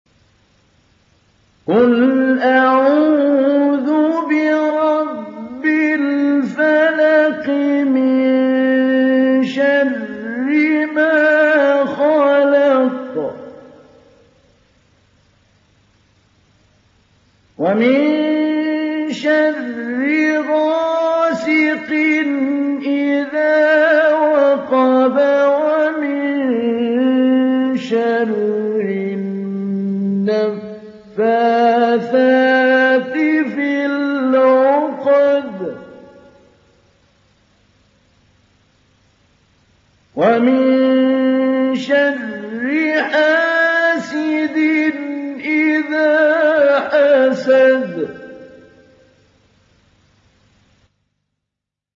دانلود سوره الفلق محمود علي البنا مجود